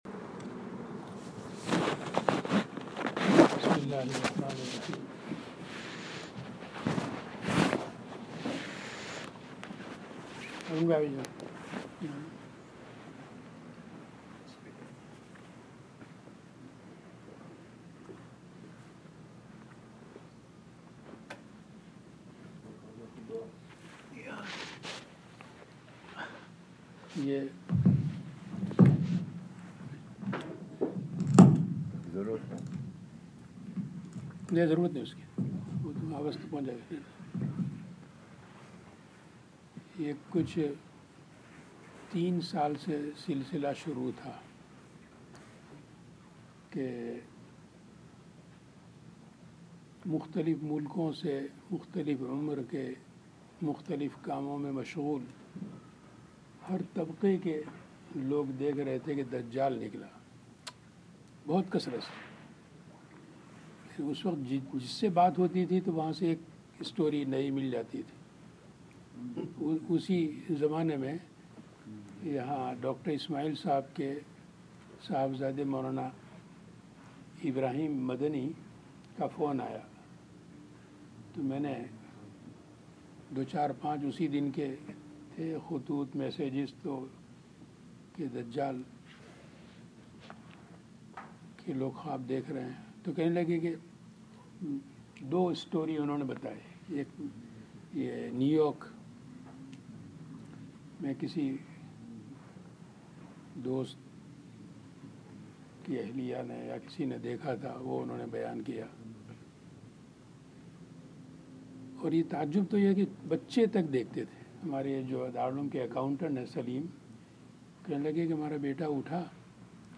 Canada 2018 - Bayaans-Speeches in Urdu by